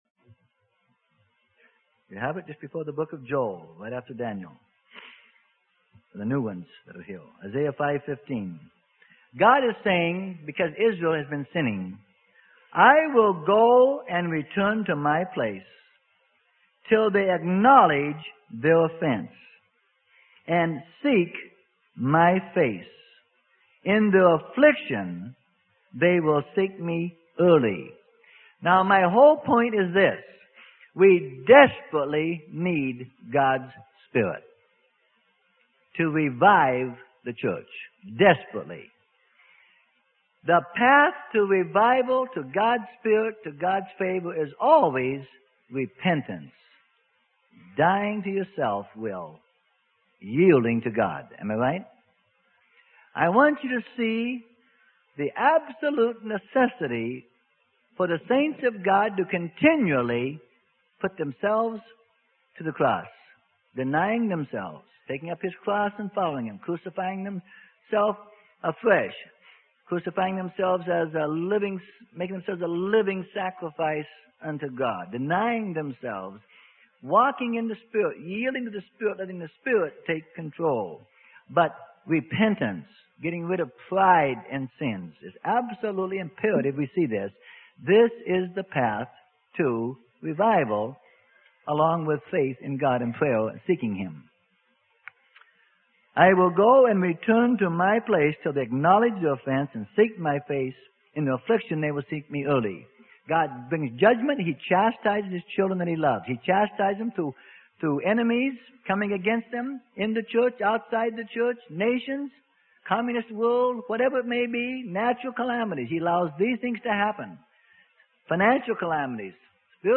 Sermon: Repent and Receive the Rain from Heaven - Freely Given Online Library